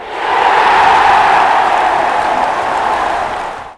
Stadium crowd roar.mp3